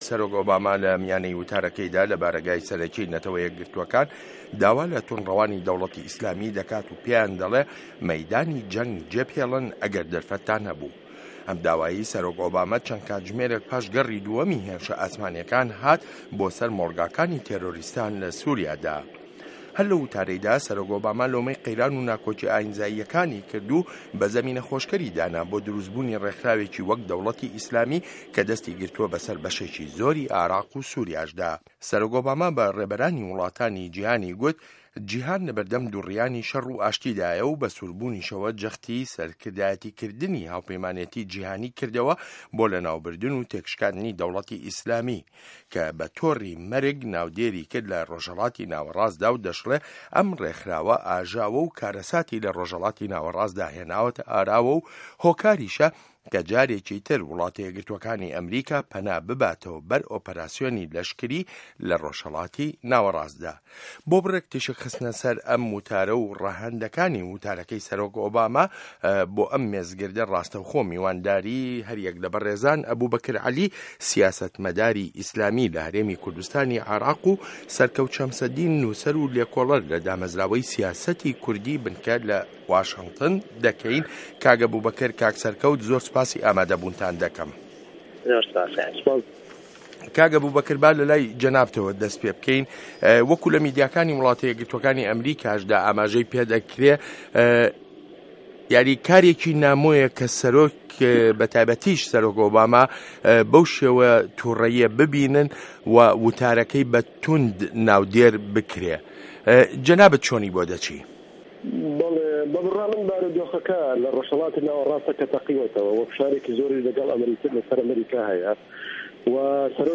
مێزگرد: سه‌رۆک ئۆباما و جیهانی ئیسلامی و داعش